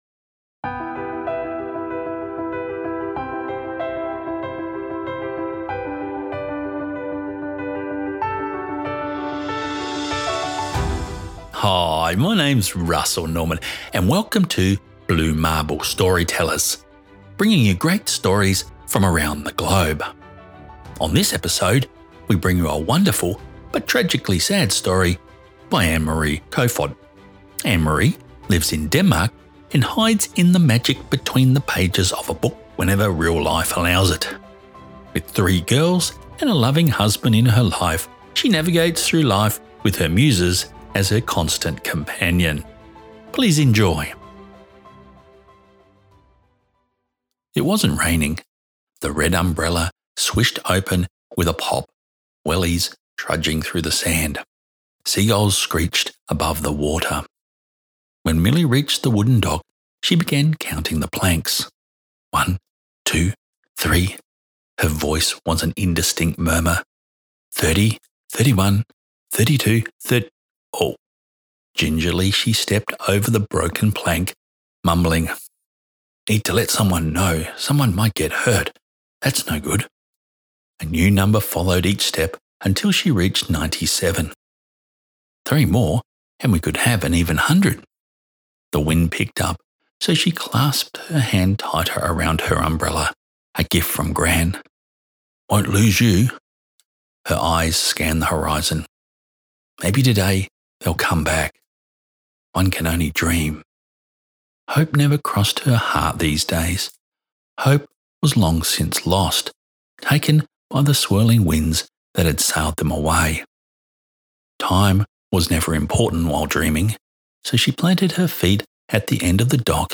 The Blue Marble Storytellers Podcast showcases unforgettable short stories read by either the author or a guest narrator.